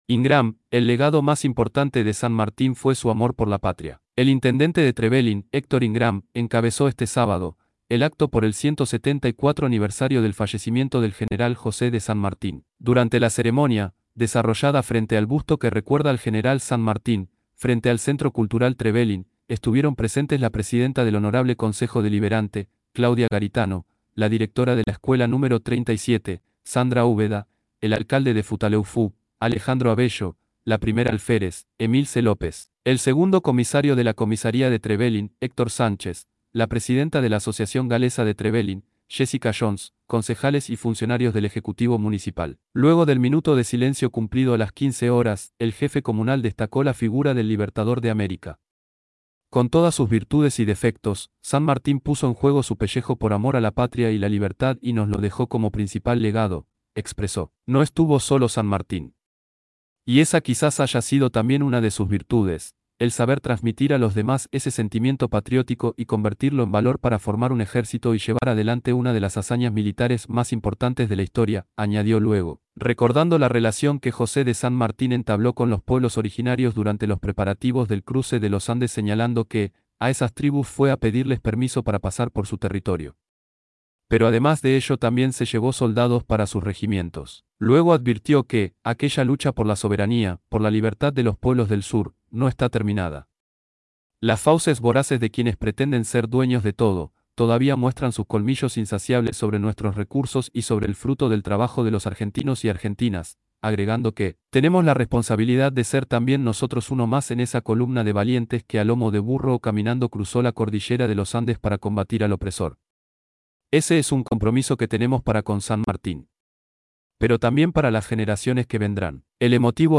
El intendente de Trevelin, Héctor Ingram, encabezó este sábado, el acto por el 174° Aniversario del fallecimiento del general José de San Martin.
acto_homenaje_san_martin_trevelin.mp3